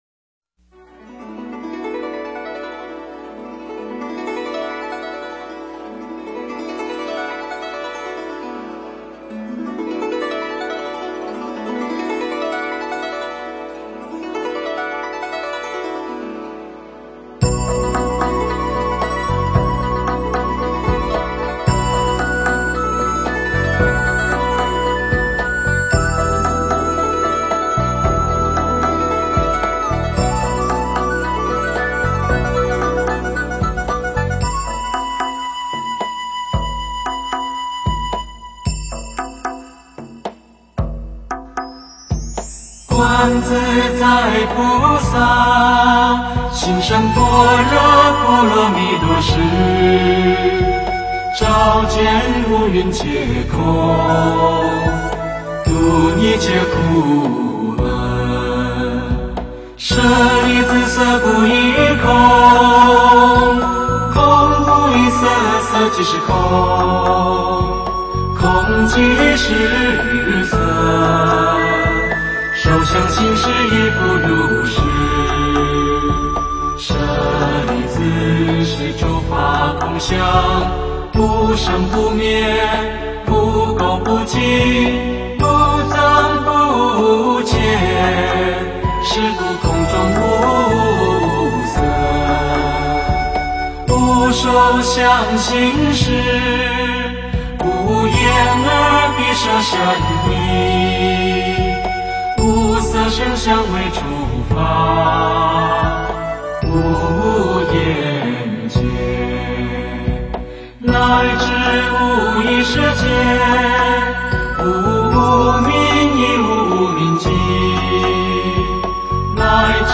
诵经
标签: 佛音诵经佛教音乐